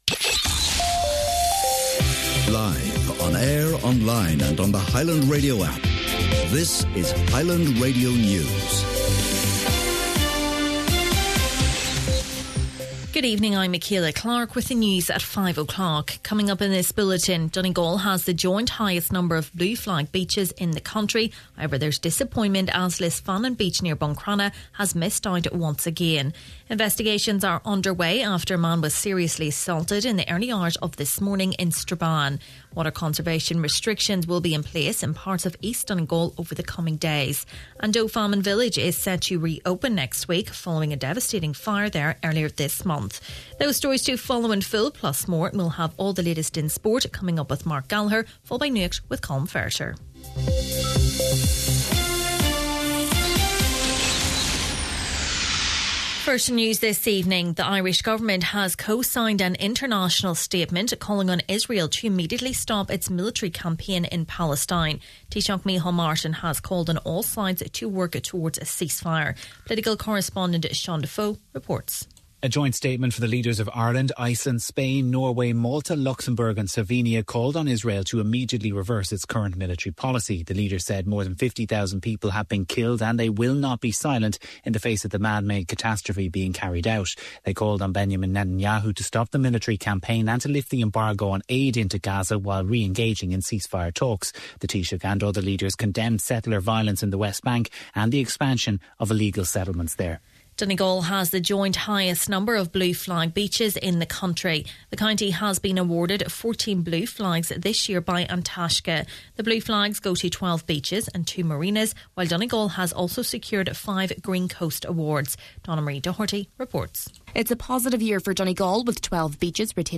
Main Evening News, Sport, Nuacht and Obituaries – Friday, May 16th